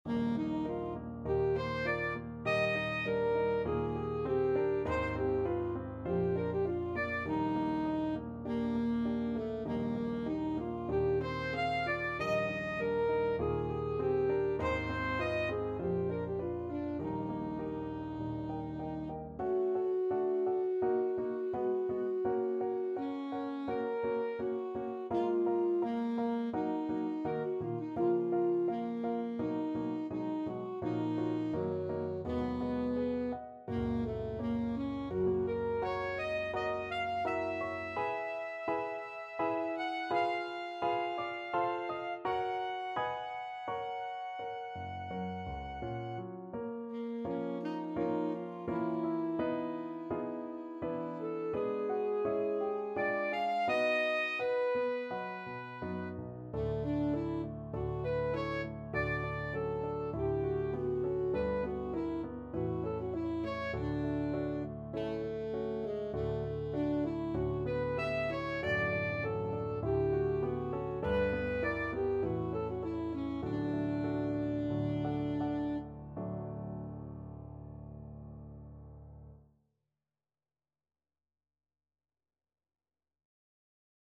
Alto Saxophone version
Alto Saxophone
Moderato
4/4 (View more 4/4 Music)
F#4-F#6
Classical (View more Classical Saxophone Music)